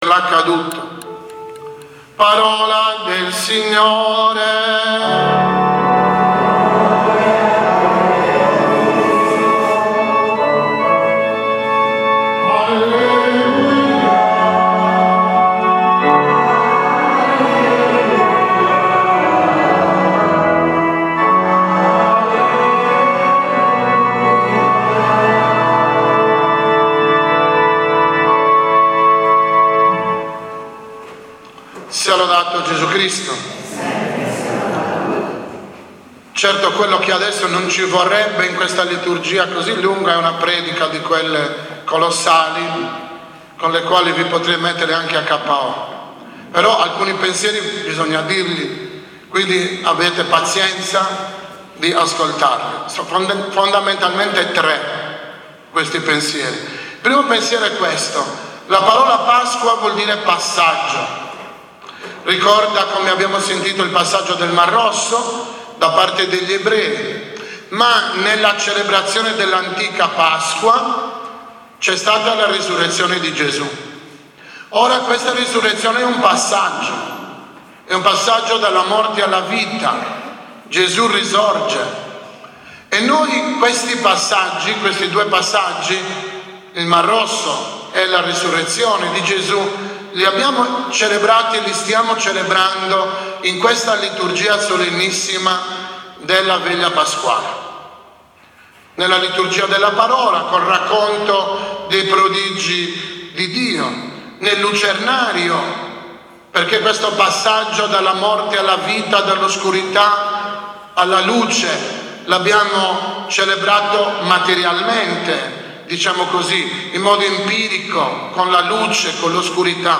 2019-OMELIA-VEGLIA-PASQUALE.mp3